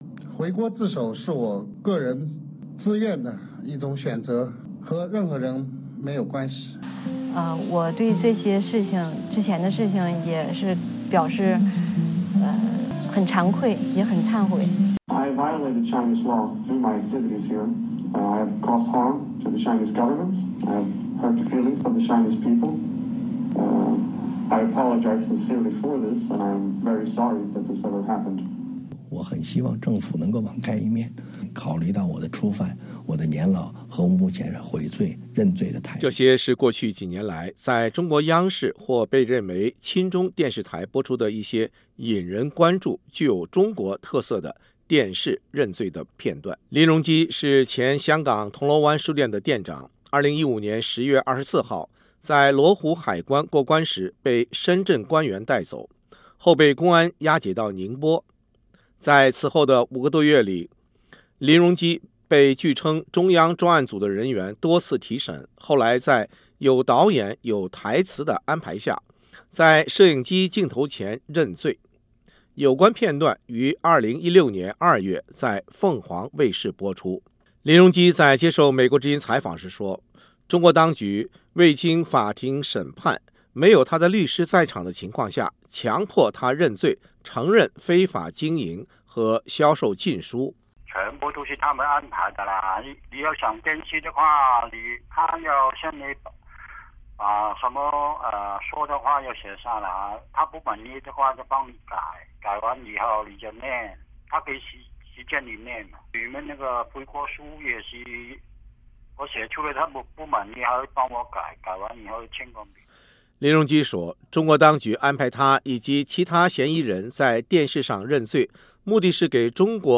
这些是过去几年来在中国央视或被认为亲中电视台播出的一些引人关注、具有中国特色的“电视认罪”的片段。